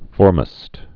(fôrməst, -măst)